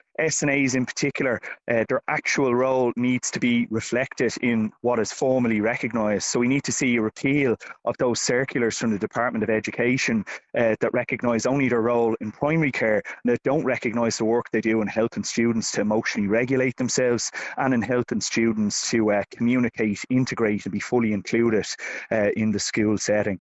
Cllr Conor Reddy, involved with Dublin North West Equality in Education, says the group wants clarity on what lies ahead for the future: